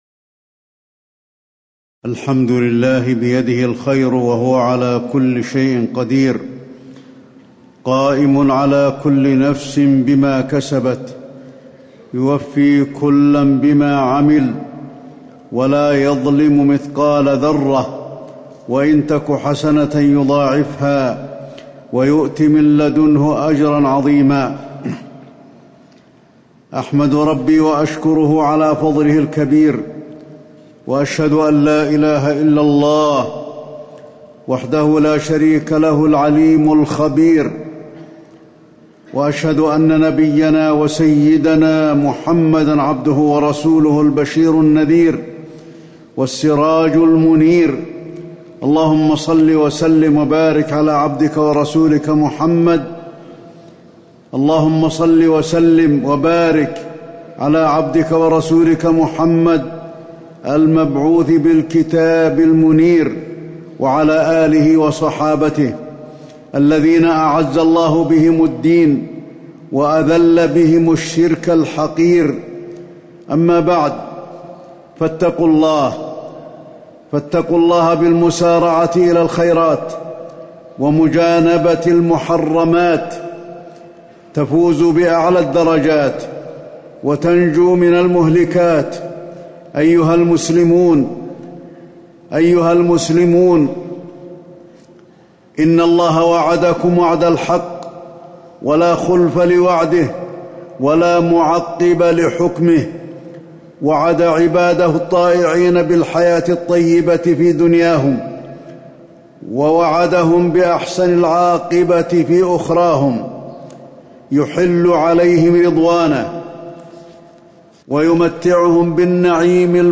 تاريخ النشر ٧ ربيع الثاني ١٤٤٠ هـ المكان: المسجد النبوي الشيخ: فضيلة الشيخ د. علي بن عبدالرحمن الحذيفي فضيلة الشيخ د. علي بن عبدالرحمن الحذيفي مجاهدة النفس والحذر من كيد الشيطان